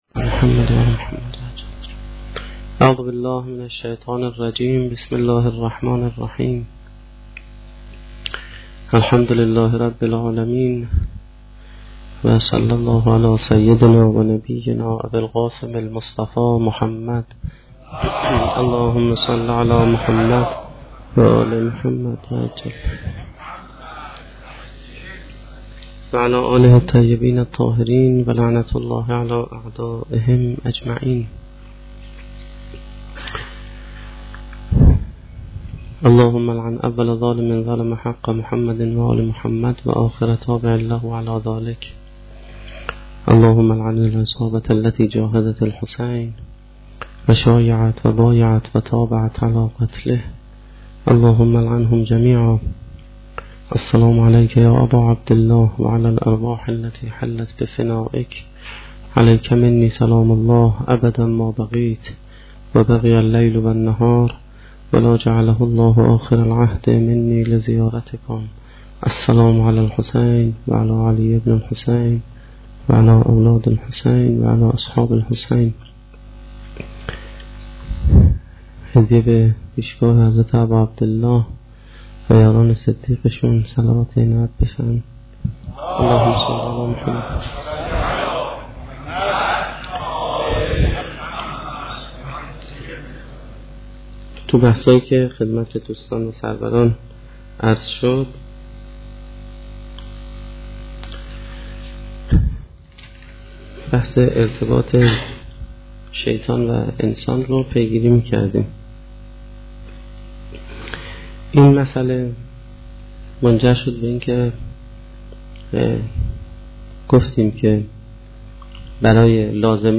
سخنرانی چهارمین شب دهه محرم1435-1392